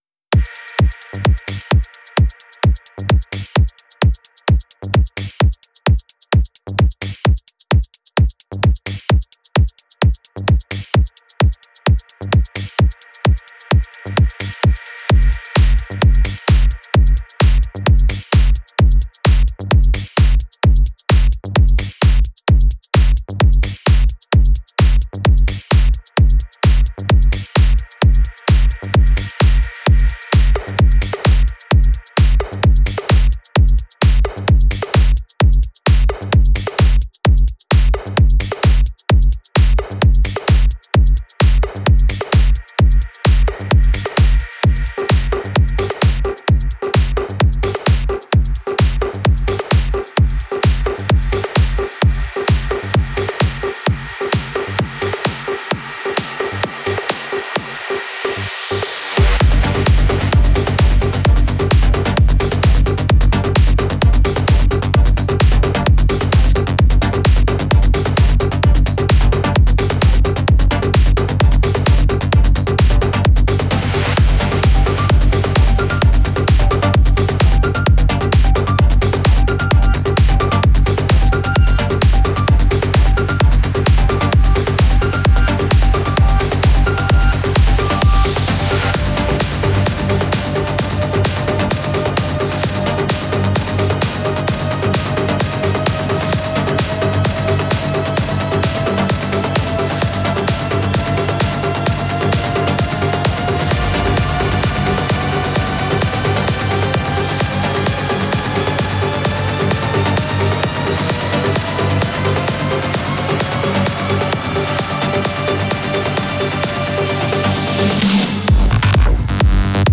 Файл в обменнике2 Myзыкa->DJ's, транс